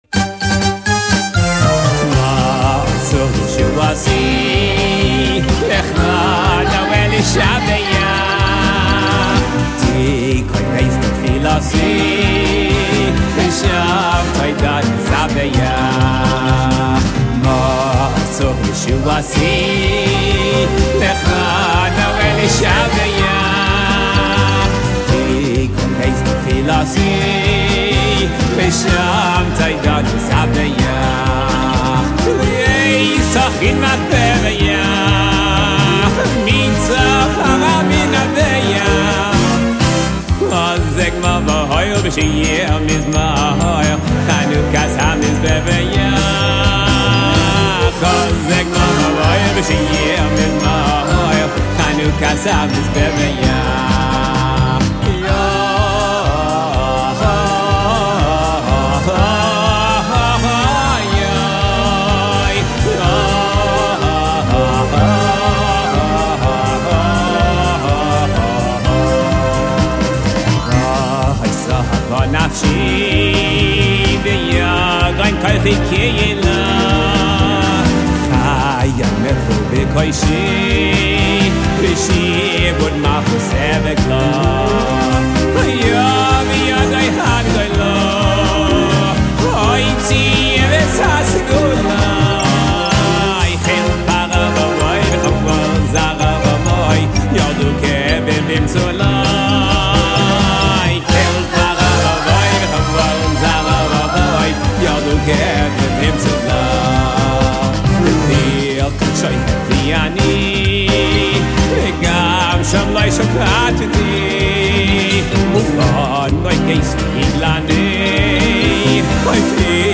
דווקא בביצוע בהבהרה חסידית!!!!!